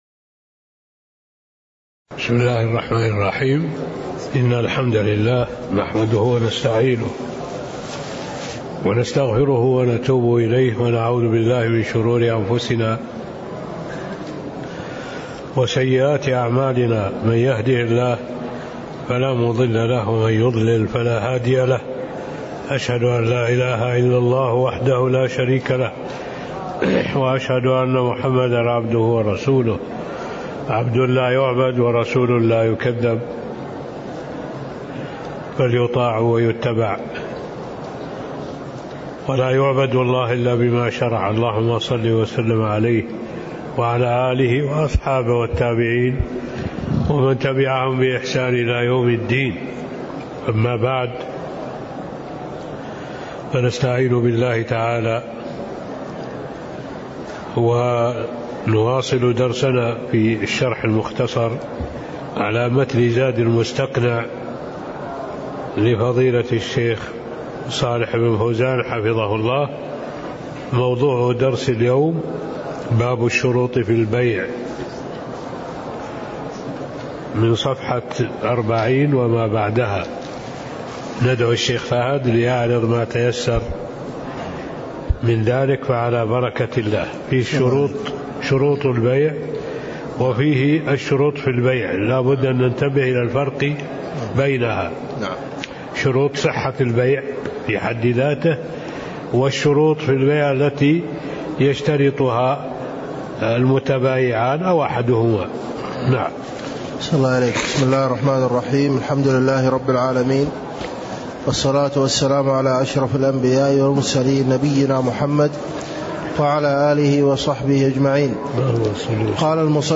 تاريخ النشر ٢١ محرم ١٤٣٥ هـ المكان: المسجد النبوي الشيخ